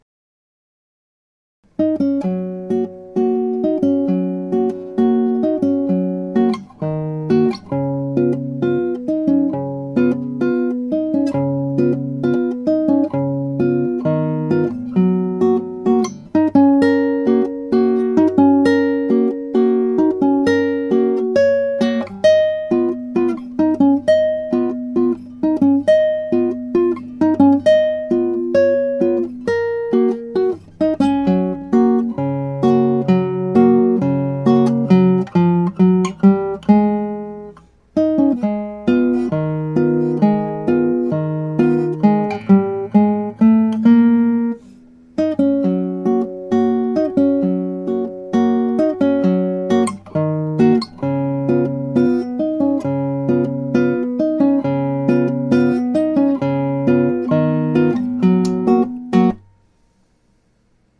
ねこふんじゃった (アマチュアギター演奏)
ギター演奏ライブラリ
(アマチュアのクラシックギター演奏です [Guitar amatuer play] )
ギターで弾くために採譜しました。ピアノではほとんどを黒鍵だけで弾く曲で嬰ヘ長調になります。音はピアノもギターも全く同じです。